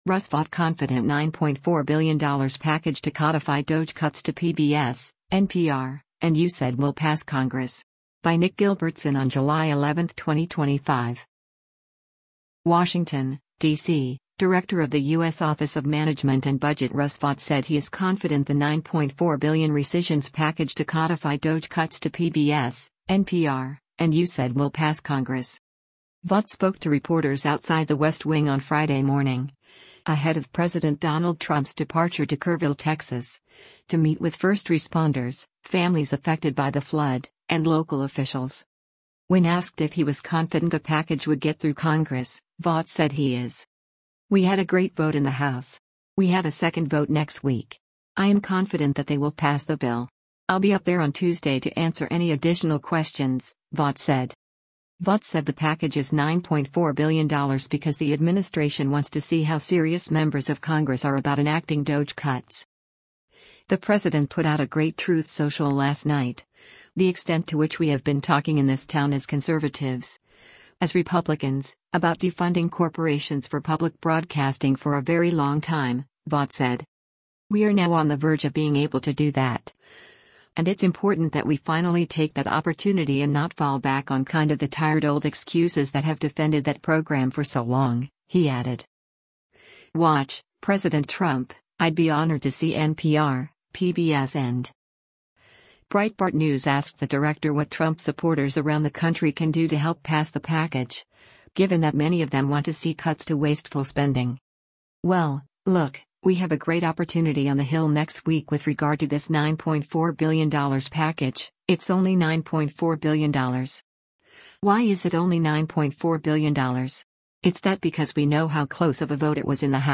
OMB Director Russ Vought speaks with the press outside of the West Wing at the White House
Vought spoke to reporters outside the West Wing on Friday morning, ahead of President Donald Trump’s departure to Kerrville, Texas, to meet with first responders, families affected by the flood, and local officials.